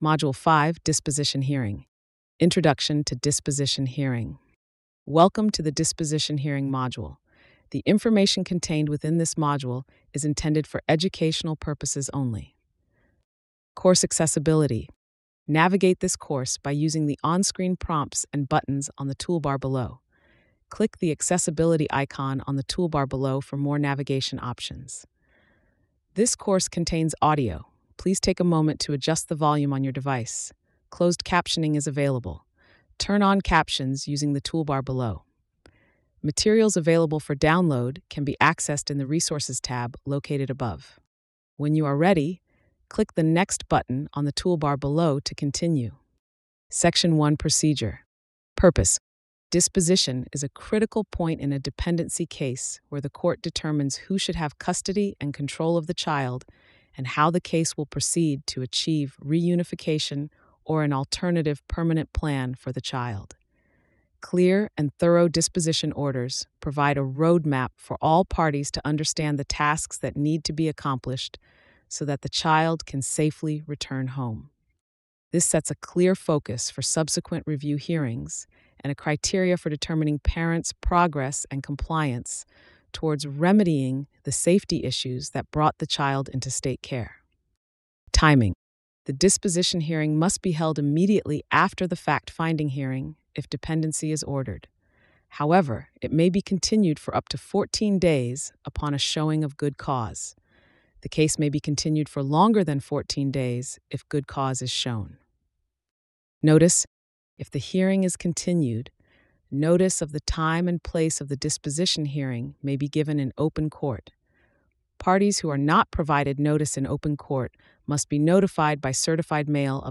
Module-5-Audio-Description.mp3